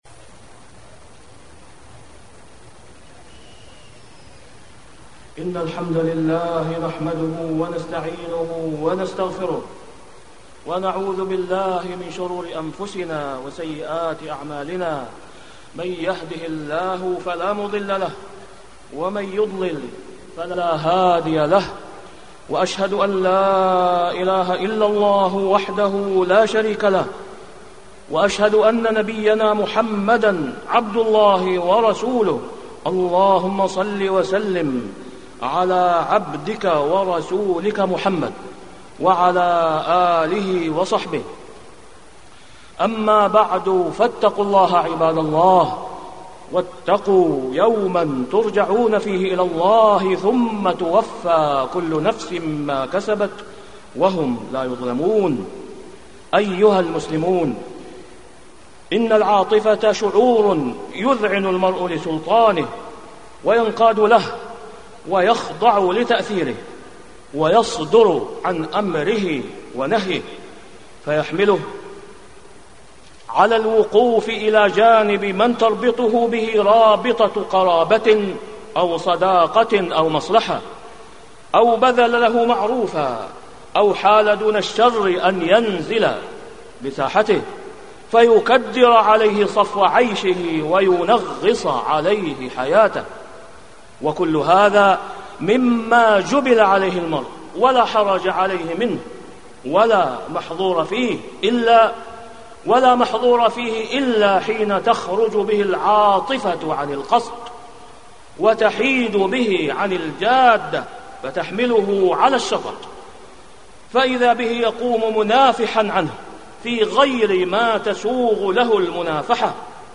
تاريخ النشر ١٣ ربيع الأول ١٤٢٩ هـ المكان: المسجد الحرام الشيخ: فضيلة الشيخ د. أسامة بن عبدالله خياط فضيلة الشيخ د. أسامة بن عبدالله خياط الحق مقدم على النفوس والأهواء